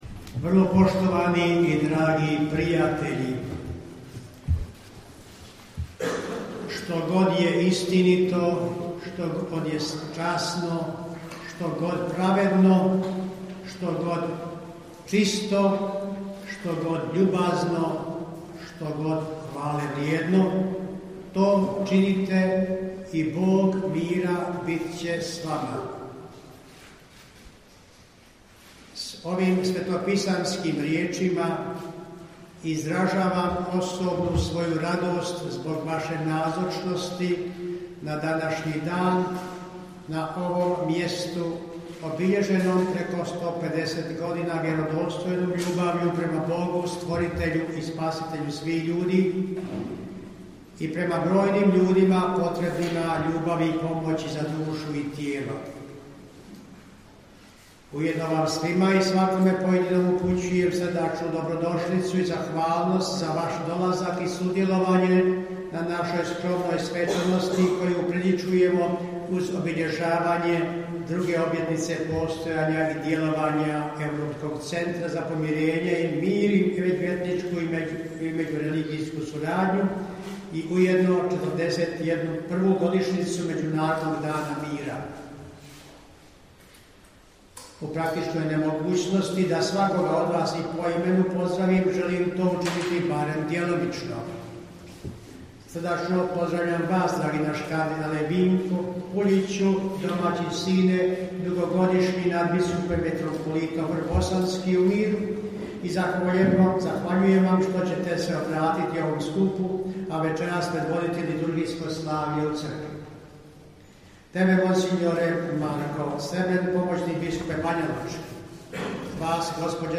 AUDIO: GOVOR BISKUPA KOMARICE PRIGODOM OBILJEŽAVANJA 2. OBLJETNICE UTEMELJENJA EUROPSKOG CENTRA ZA MIR I SURADNJU U BANJOJ LUCI - BANJOLUČKA BISKUPIJA
U organizaciji Banjolučke biskupije i Paneuropske unije, a u suradnji sa Zakladom Konrad Adenauer, u utorak, 20. rujna 2022. u Banjoj Luci u Kući susreta „Marija Zvijezda“ (bivši trapistički samostan) upriličeno je obilježavanje 2. obljetnice utemeljenja Europskog centra za mir i suradnju. Biskup banjolučki i kopredsjednik Kuratorija Europskog centra mons. Franjo Komarica uputio je pozdravni i uvodni govor koji prenosimo u cijelosti: